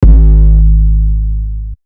Waka KICK Edited (4).wav